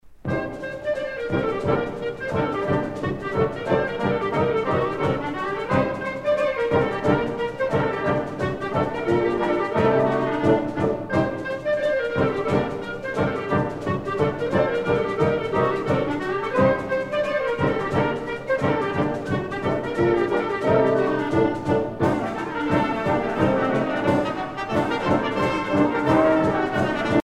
danse : horo (Bulgarie)